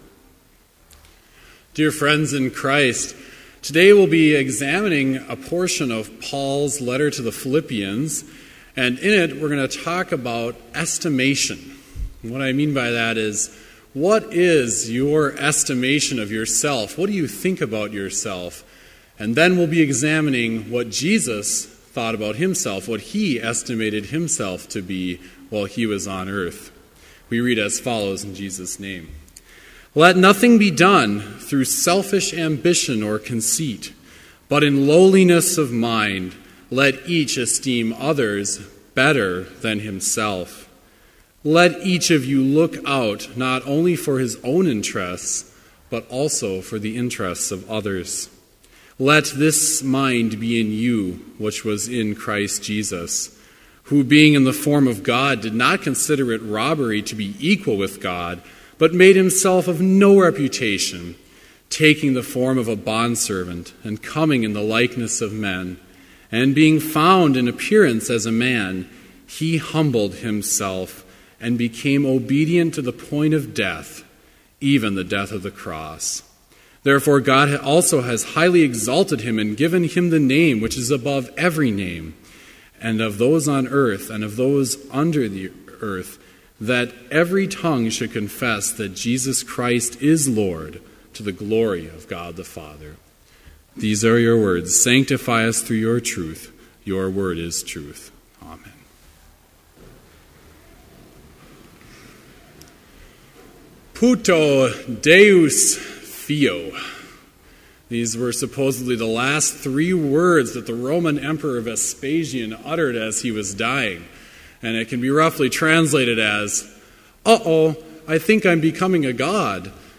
Complete service audio for Chapel - March 31, 2015